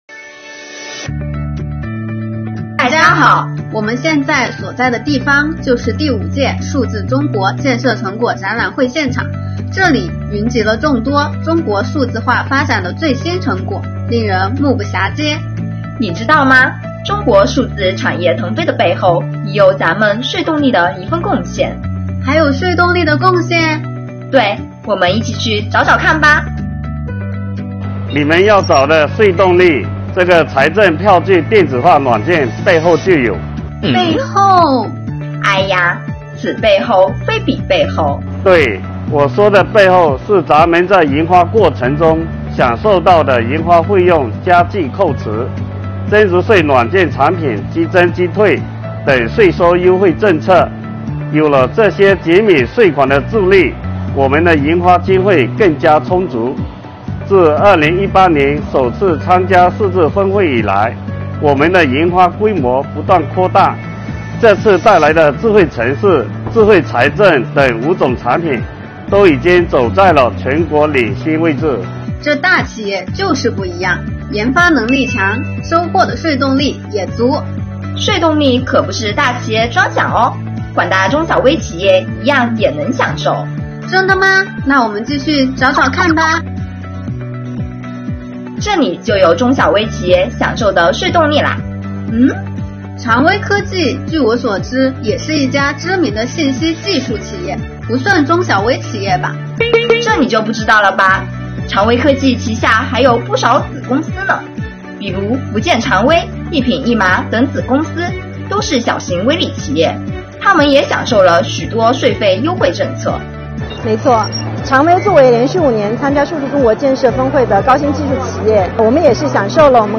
建议以后此类活动尽量用同期录音而非后期配音来展示。